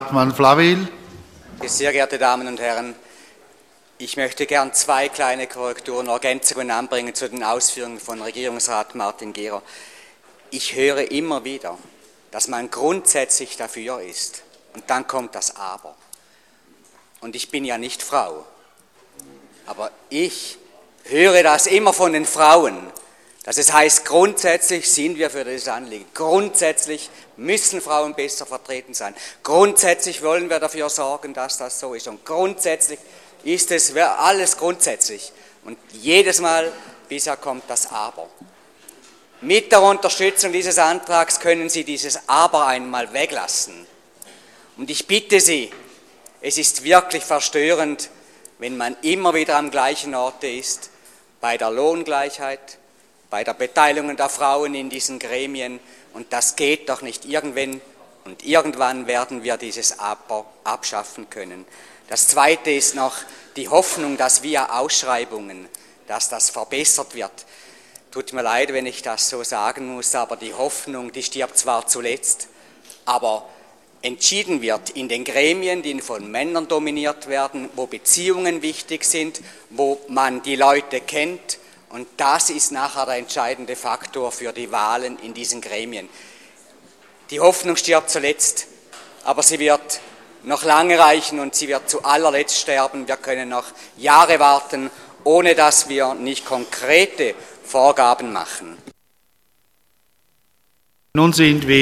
Session des Kantonsrates vom 23. bis 25. Februar 2015